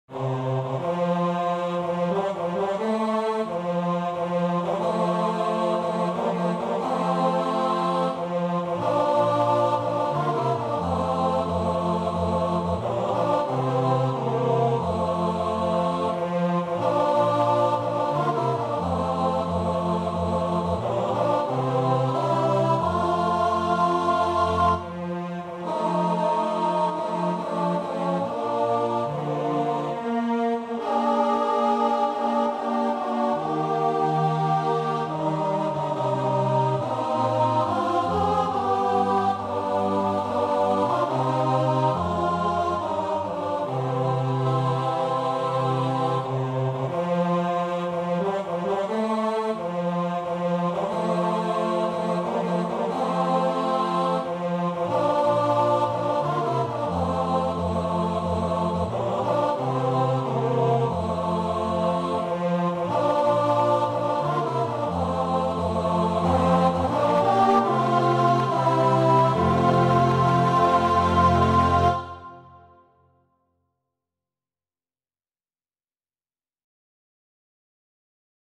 TBB (3 voix égales d'hommes) ; Partition complète.
Pièce chorale. Strophique.
Frais ; vivant ; entraînant
Tonalité : fa majeur